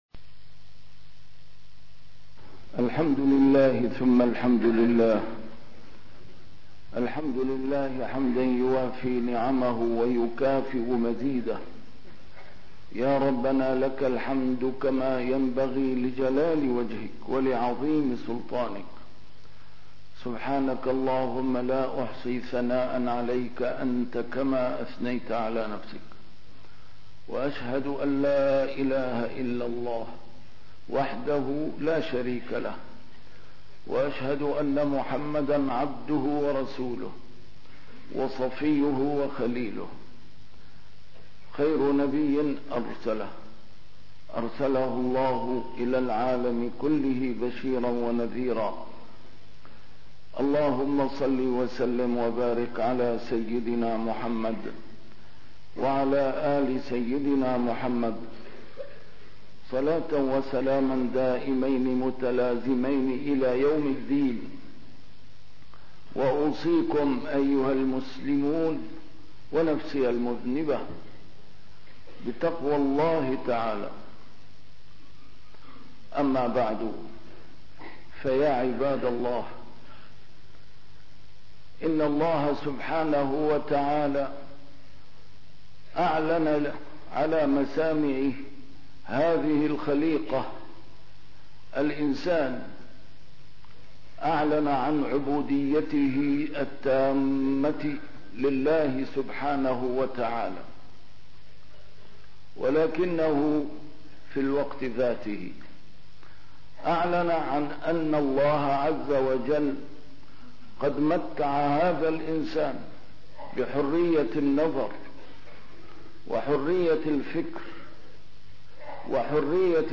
نسيم الشام › A MARTYR SCHOLAR: IMAM MUHAMMAD SAEED RAMADAN AL-BOUTI - الخطب - عاقبوا أصحاب الحرية الزائغة